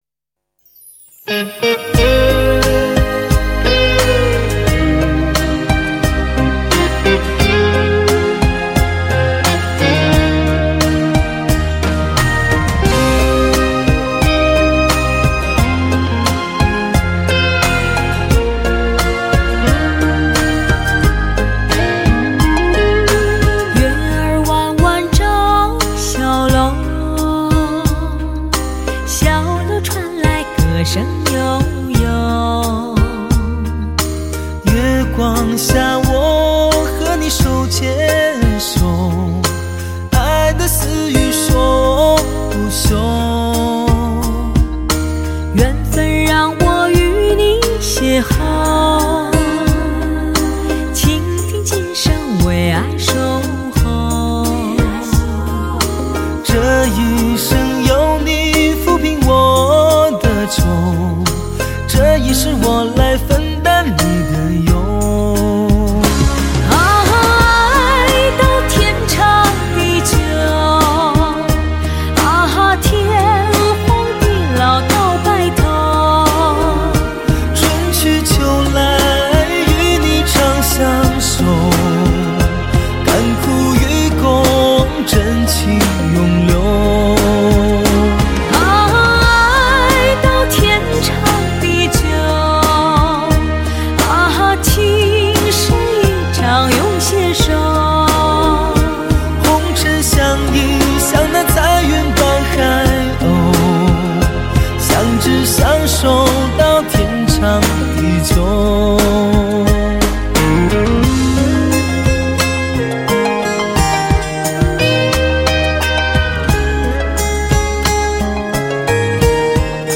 温情脉脉